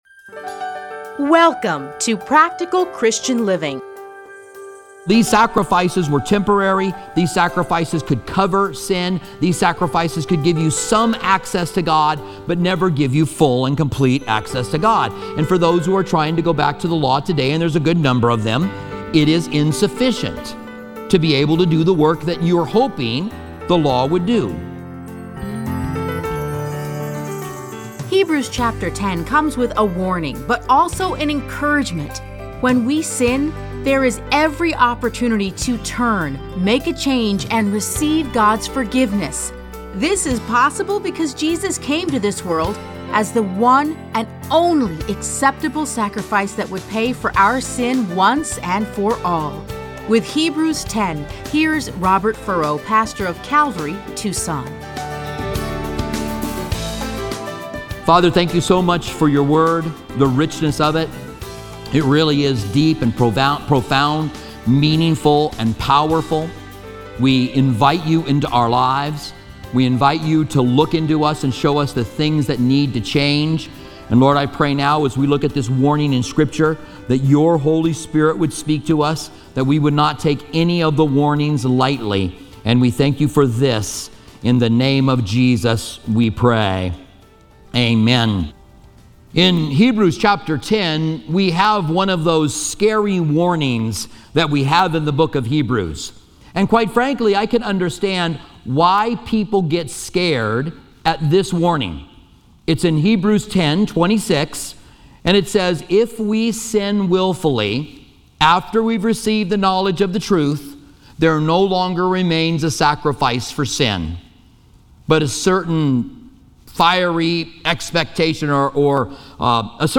Listen to a teaching from Hebrews 10:1-39.